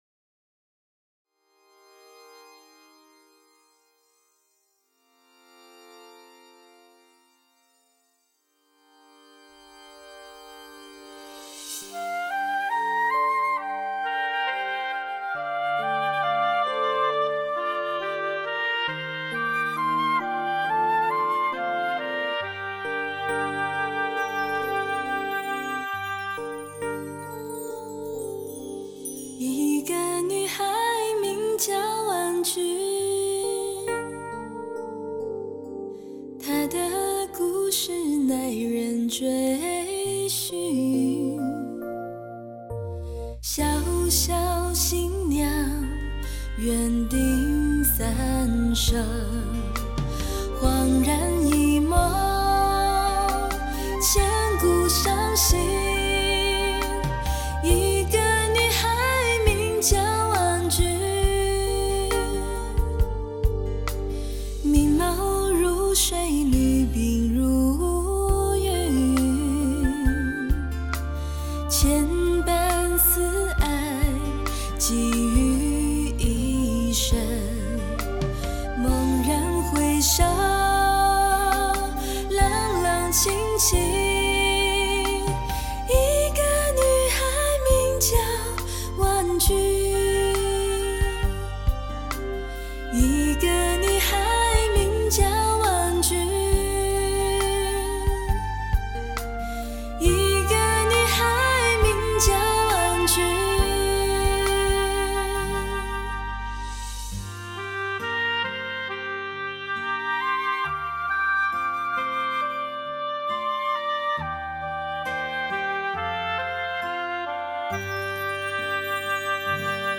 多首歌以纯吉他为主配器